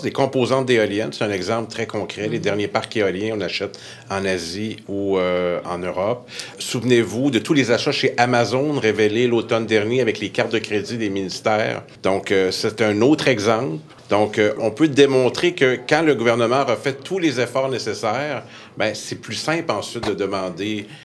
Le premier ministre a d’ailleurs profité de son passage à Saint-Patrice-de-Sherrington, en Montérégie, pour réitérer son message :